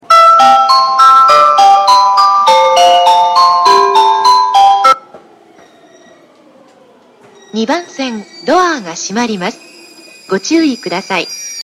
発車メロディー途中切りです。